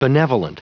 Prononciation du mot benevolent en anglais (fichier audio)
Prononciation du mot : benevolent